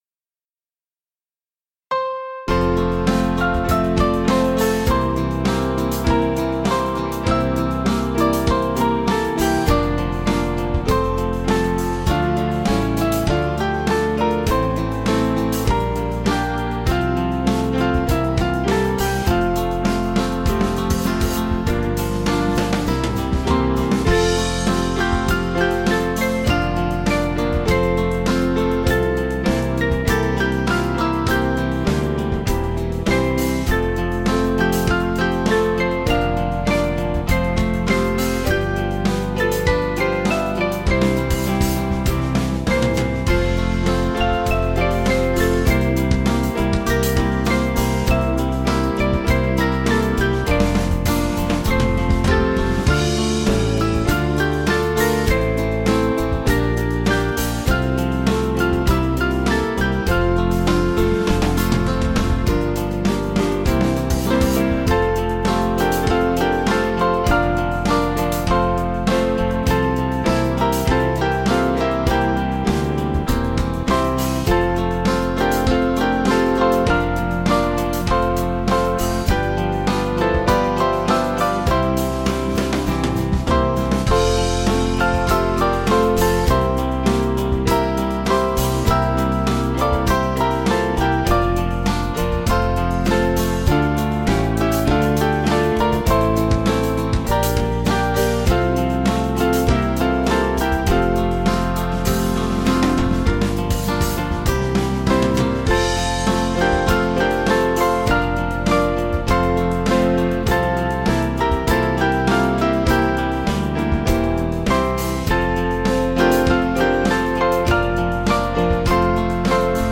Small Band